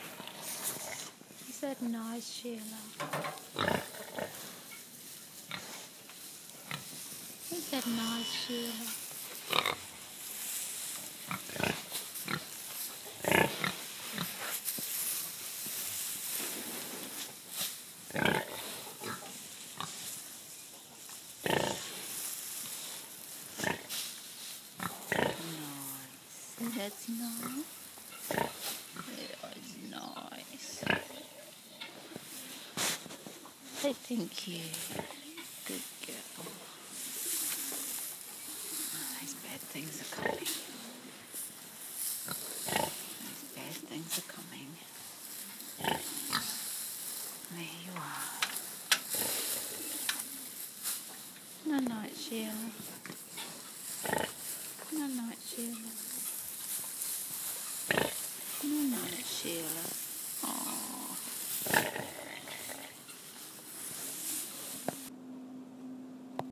Last night everything was quiet in the home barn, the wind had dropped, the birds were roosting  and the cows munched quietly in the background so while I put Sheila and the kune-kunes to bed under their respective covers, I recorded the discussion between Sheila (my big old Hereford pig) and I. Our good night talk.
In the recording Sheila speaks every time I lay another arm-full of straw along her back.  You can detect the shuffling of the straw and the little pigs higher voices in the background and you will hear her saying thank you and mimicking me at the end. Usually her good nights have two syllables but of course when I decide to record her she changes her sentence structure!